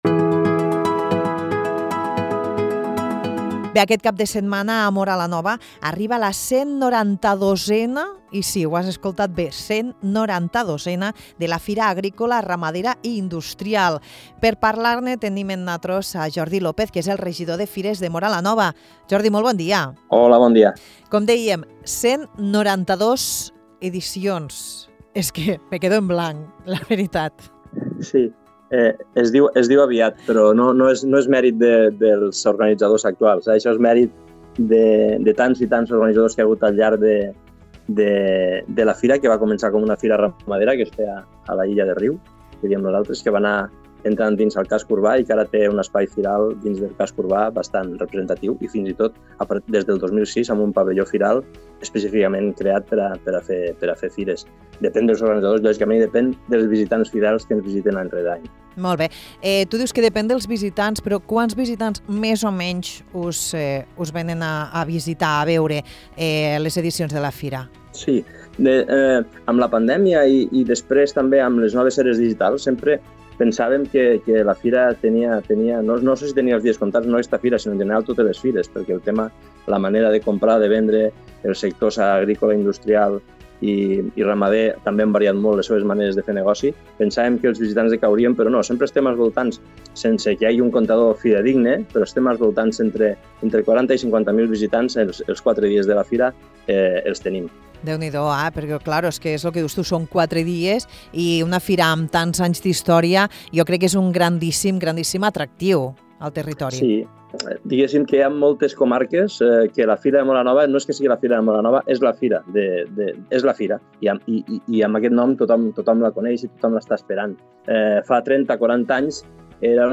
Amb el regidor de Fires de Móra la Nova, Jordi López, parlem sobre la 192a edició de la Fira Agrícola, Ramadera i Industrial.